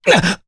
Mitra-Vox_Damage_jp_02.wav